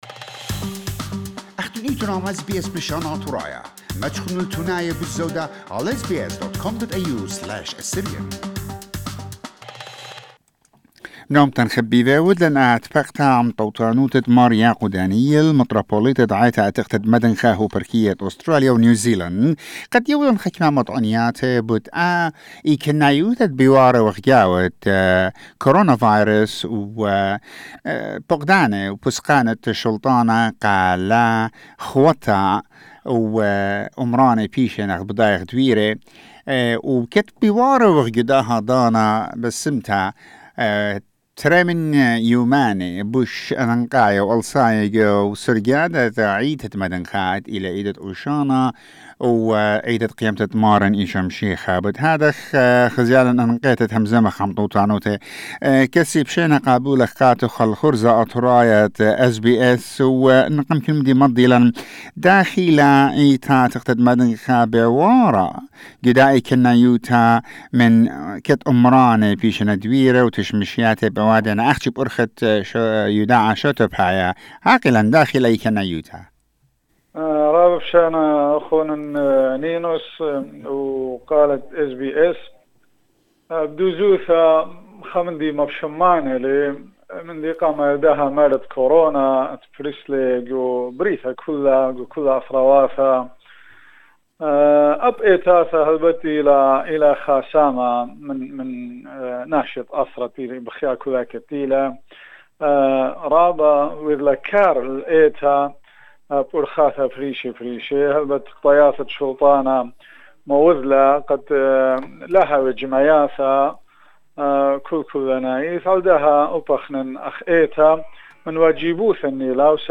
His Beatitude Mar Yako Daniel, Metropolitan of the Ancient Church of the East, presiding over the Diocese of Australia, New Zealand delivers his message about the celebration of the holy week of palm and Easter Sundays.